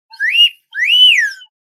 Funny Cartoon Slide Whistle Sound Effect
Description: Funny cartoon slide whistle sound effect. This cartoon whistle sound effect is funny, playful, and bright. Perfect for adding cheerful, energetic, and whimsical vibes to animations, games, or videos.
Funny-cartoon-slide-whistle-sound-effect.mp3